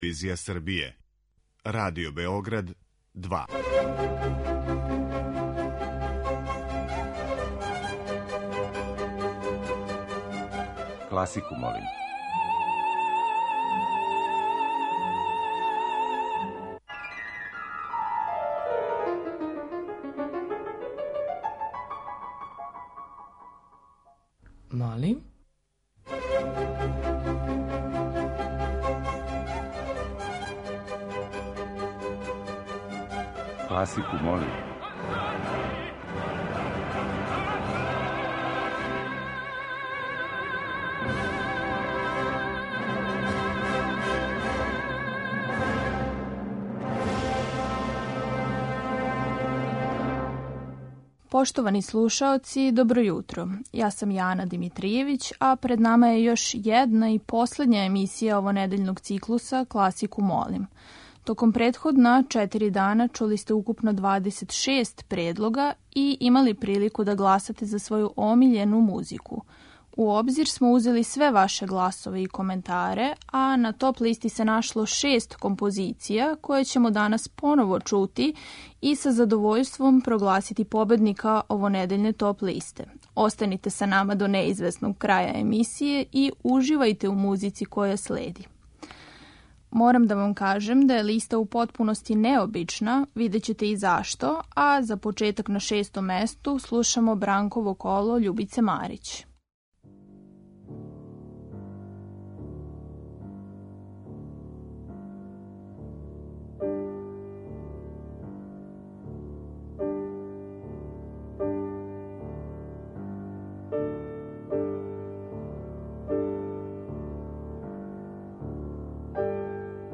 Избор за топ-листу класичне музике Радио Београда 2